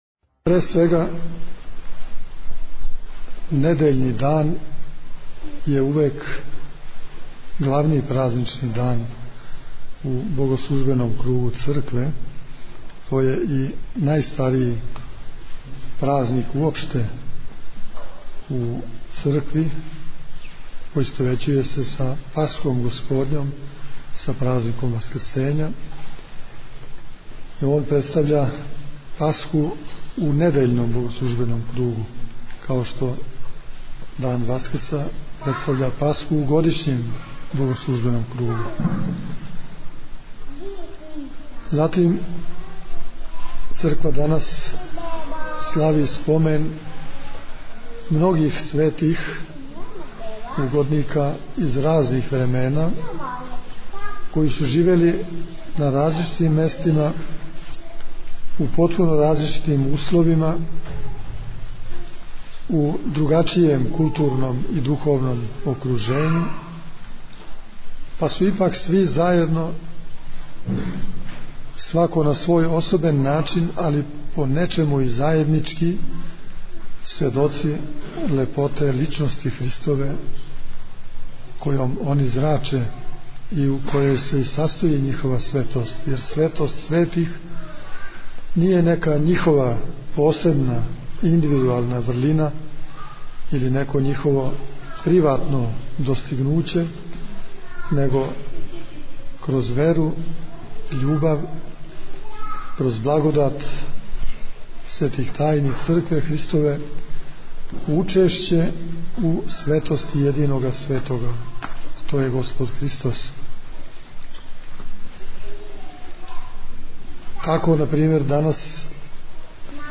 У другу недељу Великог поста, 28. фебруара текуће године, осим редовних свештених спомена, житељи Ветерника молитвено су прославили своју храмовну славу, преподобног и богоносног оца нашег Симеона Мироточивог.
Беседа Епископа Иринеја Фотографије
veternik_vladika_irinej_2010.mp3